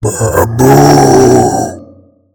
infinitefusion-e18/Audio/SE/Cries/MAMOSWINE.mp3 at releases-April